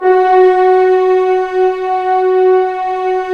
Index of /90_sSampleCDs/Roland - Brass, Strings, Hits and Combos/ORC_Orc.Unison p/ORC_Orc.Unison p